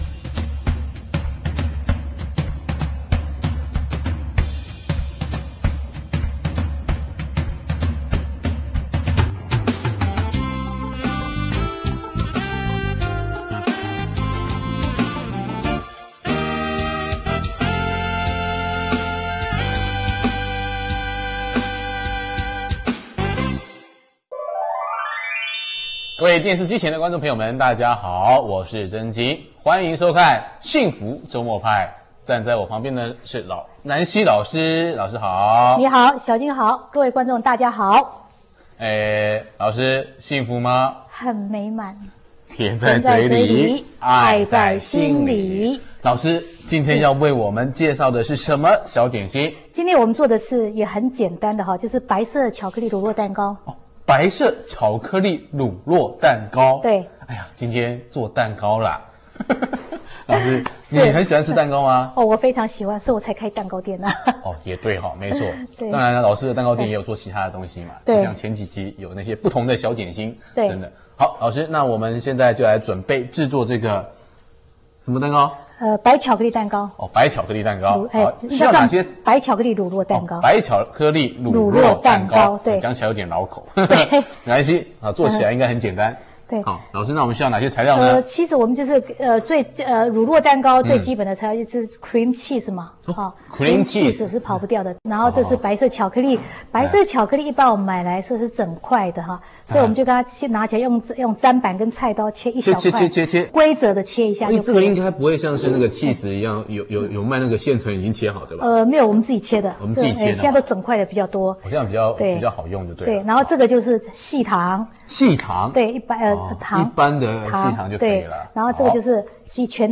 [蛋糕] 白色巧克力乳酪蛋糕(電視教學).